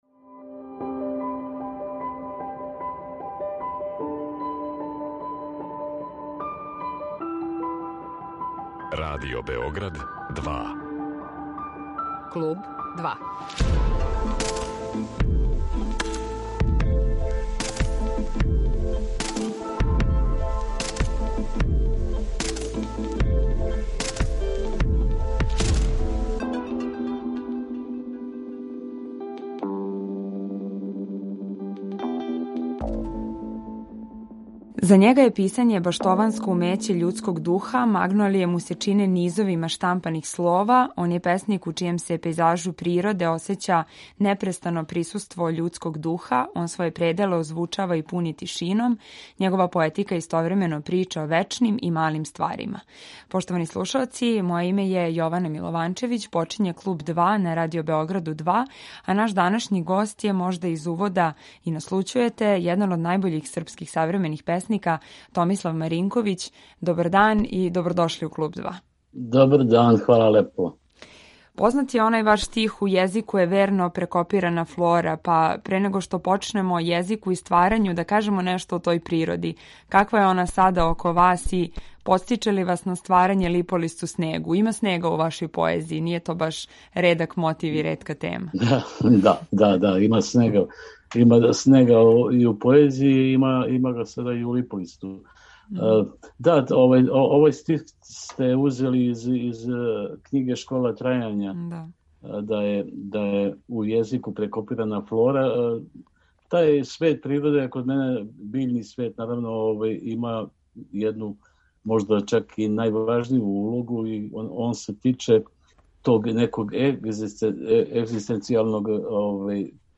Завирићемо и у његову песничку радионицу, питати га о начину писања, поетским саговорницима, младим људима који пишу. Разговор води: